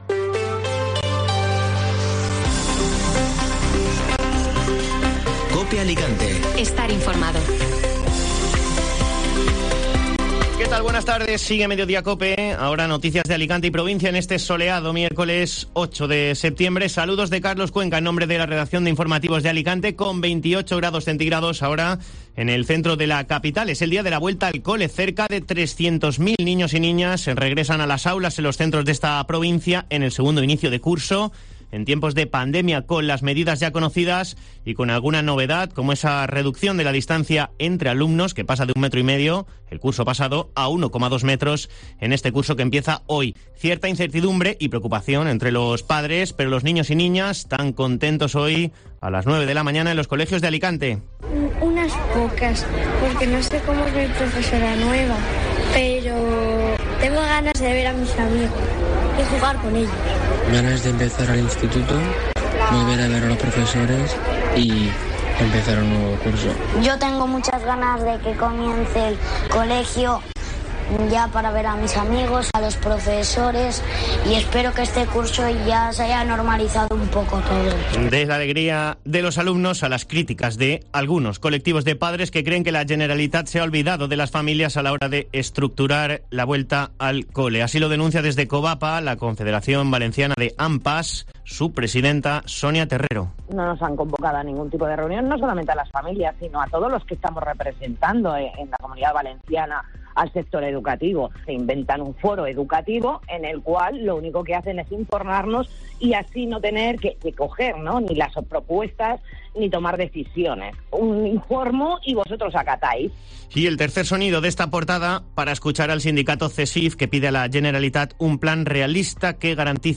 Informativo Mediodía COPE Alicante (Miércoles 8 de septiembre)